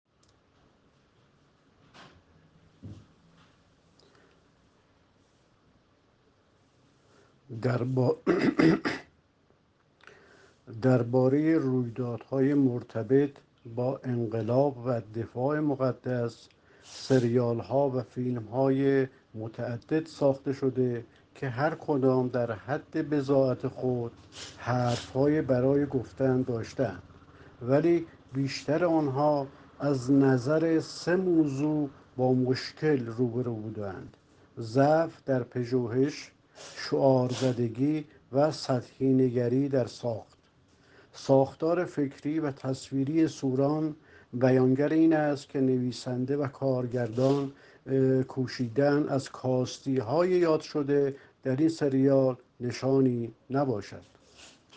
یک منتقد سینما گفت: «سوران» ساخته سروش محمدزاده کاری موفق در حوزه دفاع مقدس و بر پایه اقتباس است.